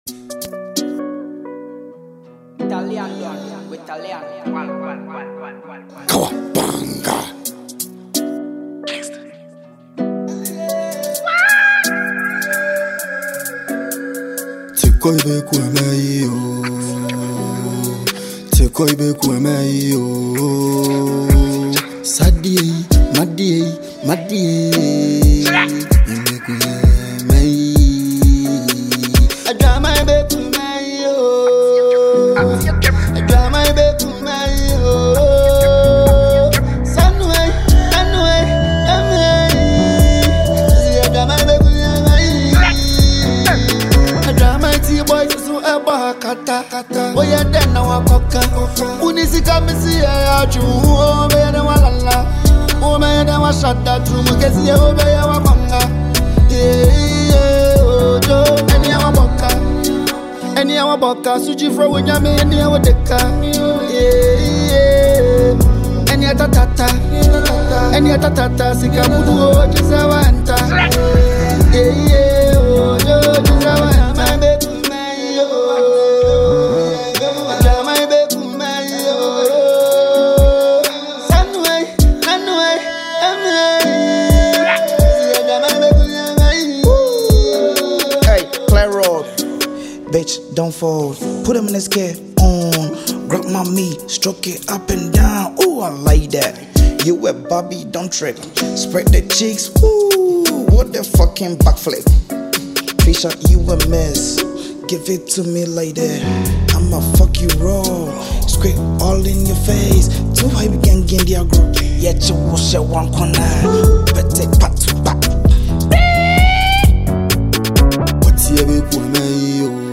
a track that blends street vibes with raw lyrical delivery.